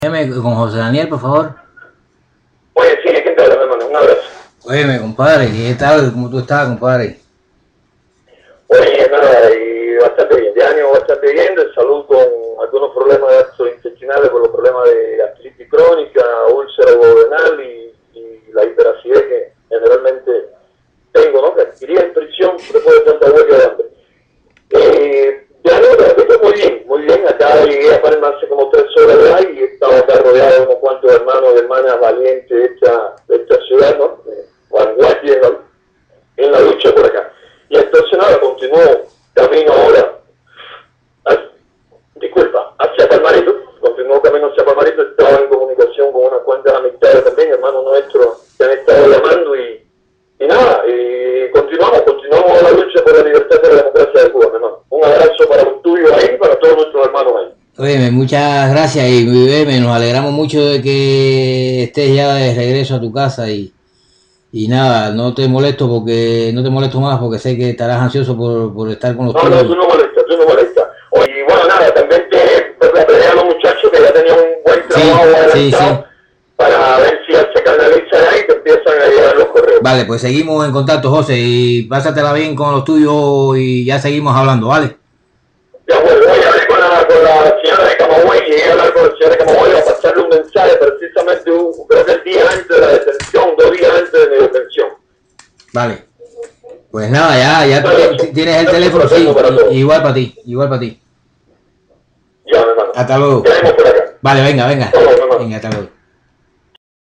Declaraciones de José Daniel Ferrer para el OCDH